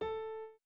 01_院长房间_钢琴_04.wav